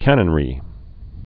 (kănən-rē)